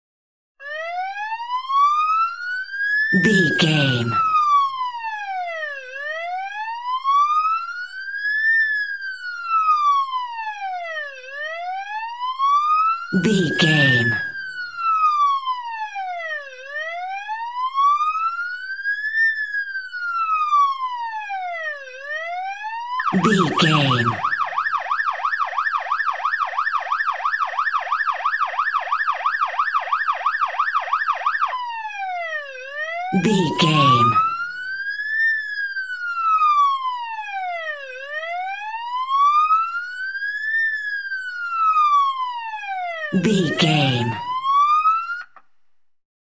Police Car Siren
Sound Effects
urban
chaotic
emergency